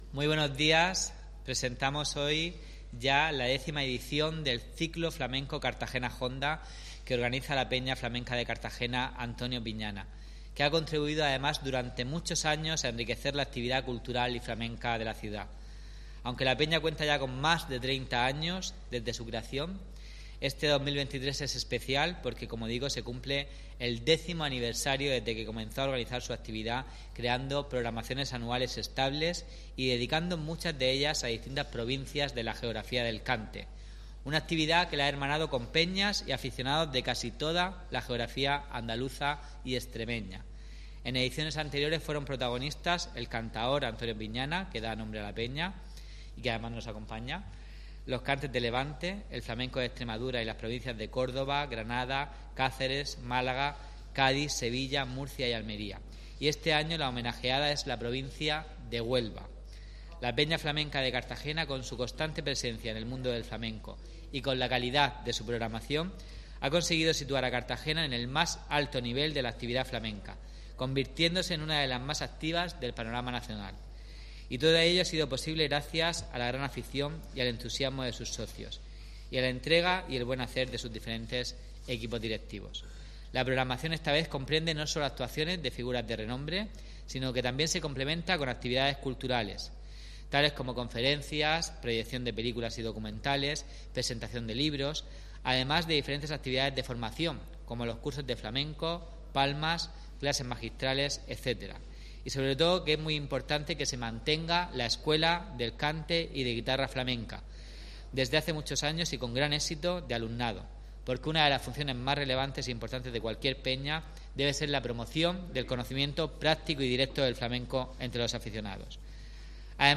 Audio: Presentaci�n de Cartagena Jonda (MP3 - 9,99 MB)